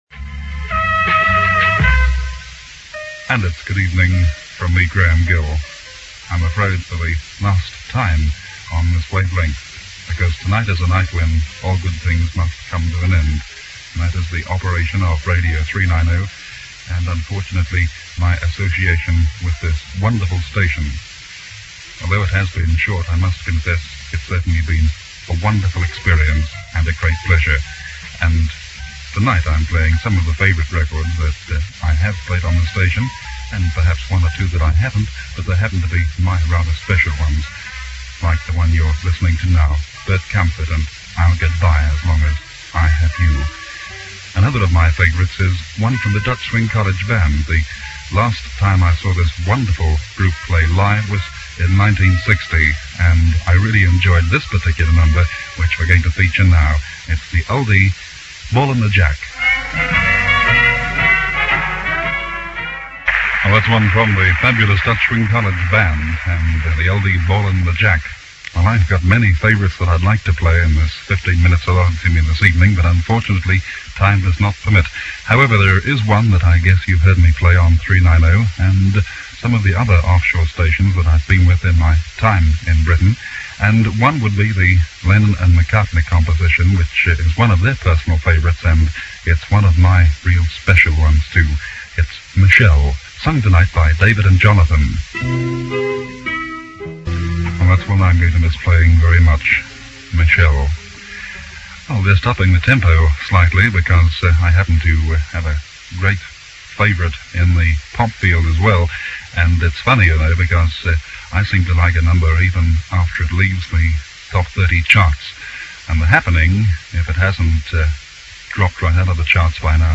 says his goodbyes for the same programme